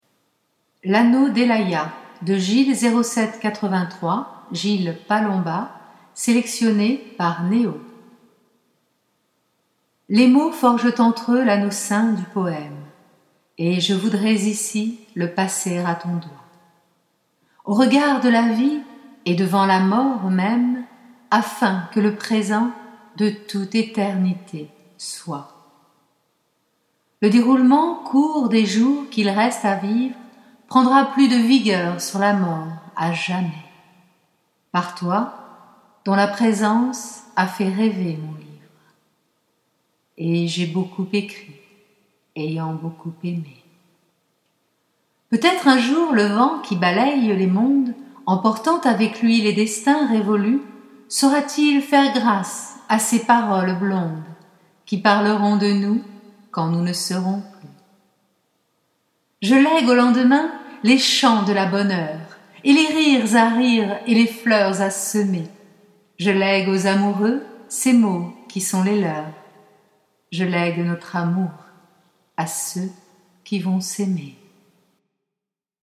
iPagina’Son vous offre la lecture d’un poème d’amour…